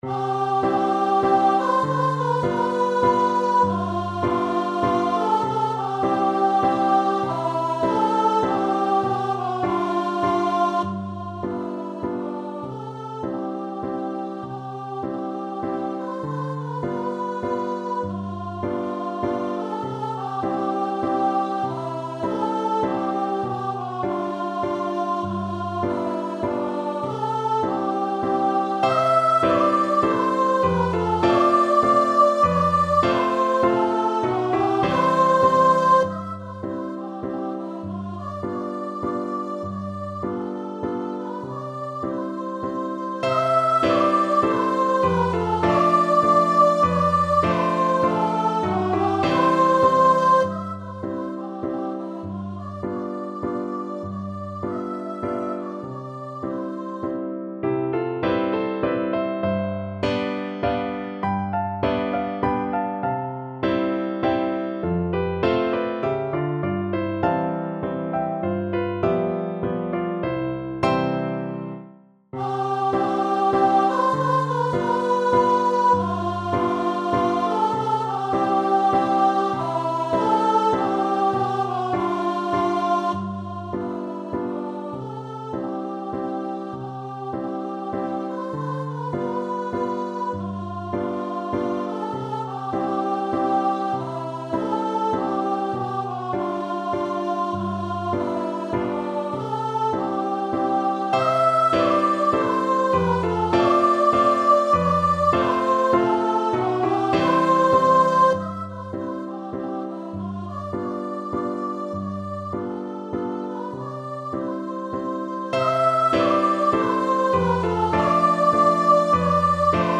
Andantino (View more music marked Andantino)
3/4 (View more 3/4 Music)
Neapolitan Song